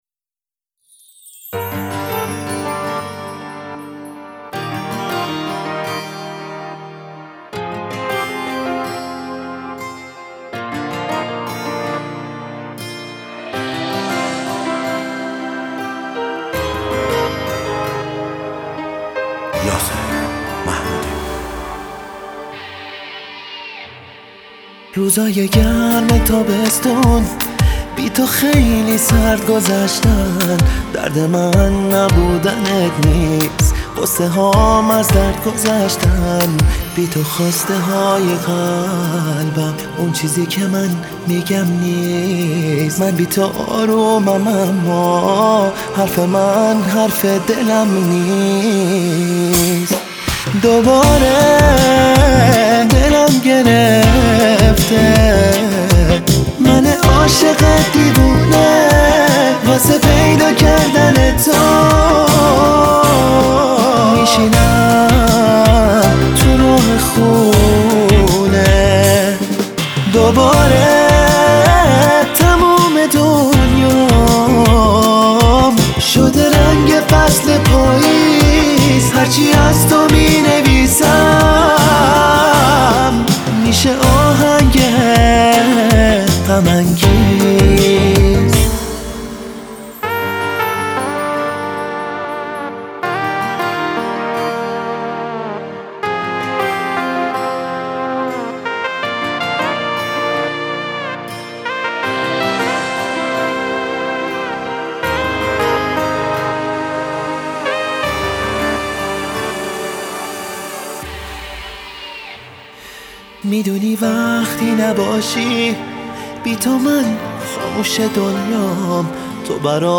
غمگین ، پاپ